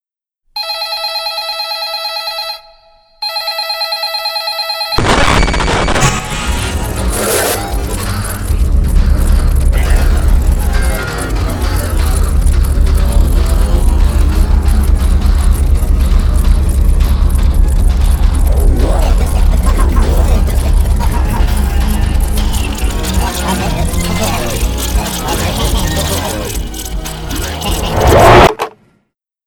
It's audio from that one video but distorted even more